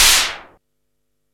SIMMONS SDS7 9.wav